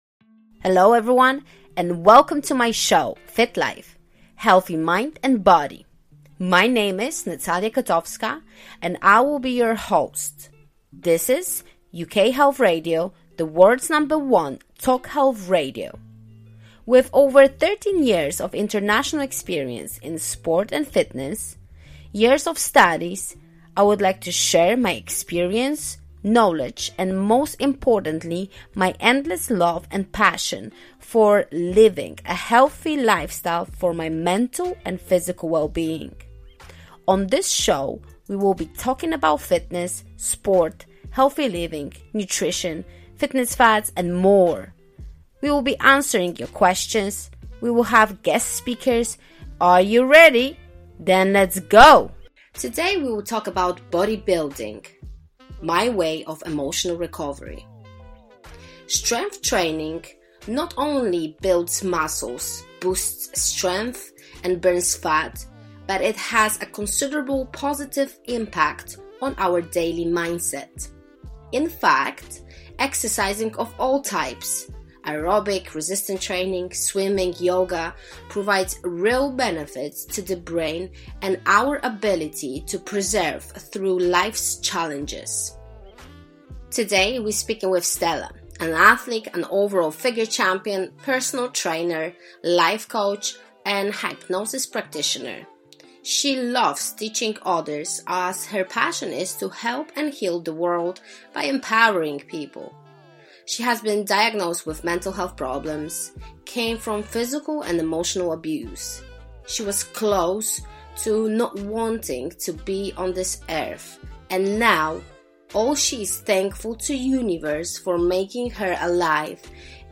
Your questions are answered by experts, and each episode includes group exercise.